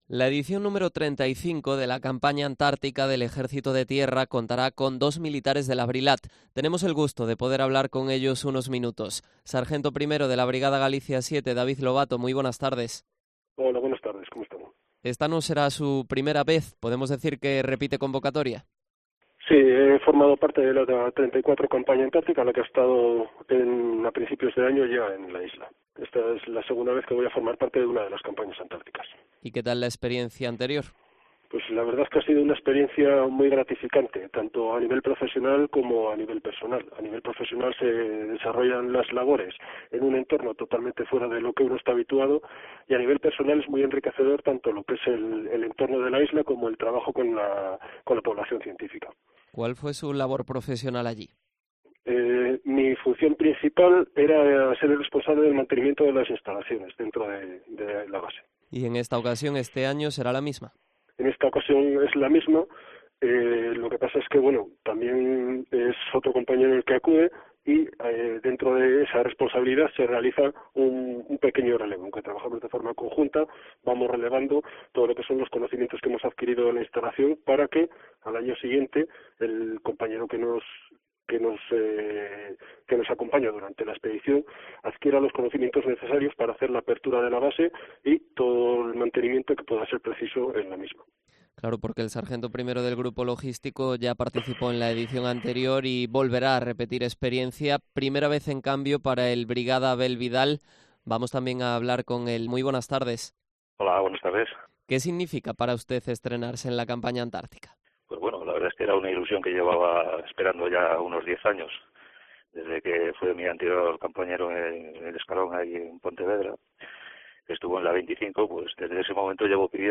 Entrevista a los militares de la Brilat que participarán en la XXXV Campaña Antártica